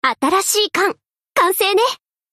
Ship Voice Rodney Construction.mp3